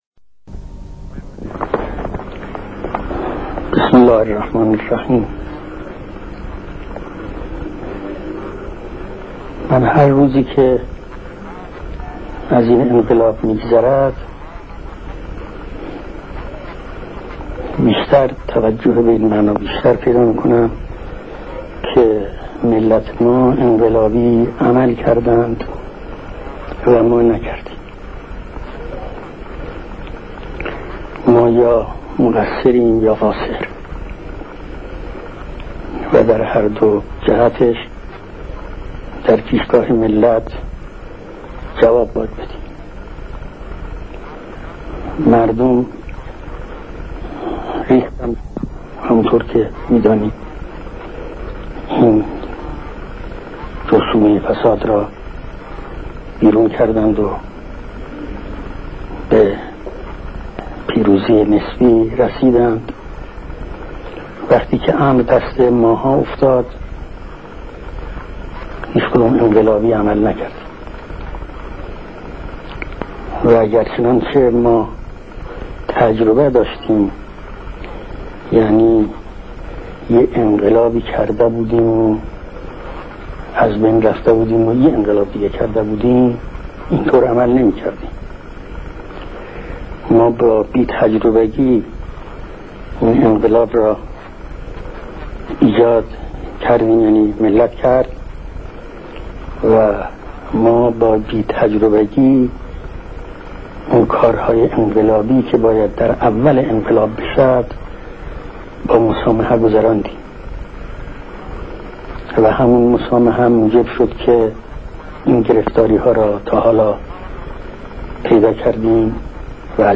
Ayatollah Khomeini made this speech on 26 of Mordad, 1358/1979.